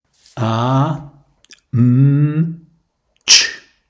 Sprachsignale für a / m / tsch